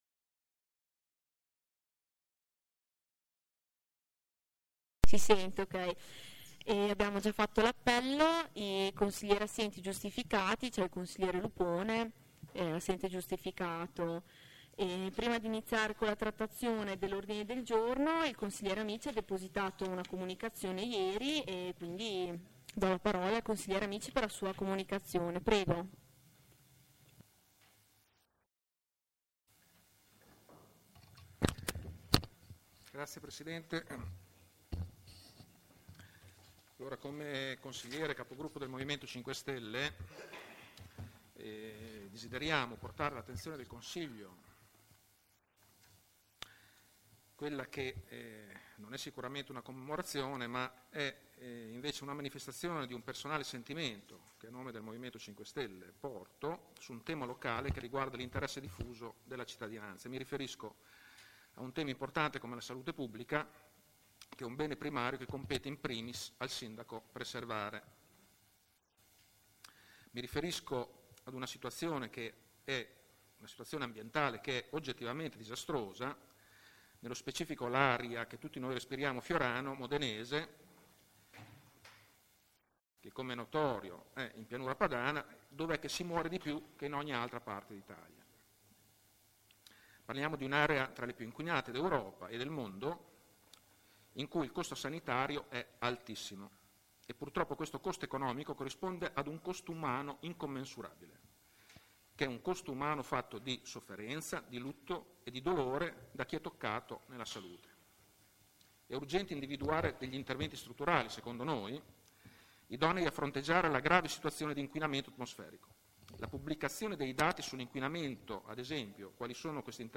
Registrazioni video Consiglio Comunale
Archivio delle registrazioni video in streaming del Consiglio Comunale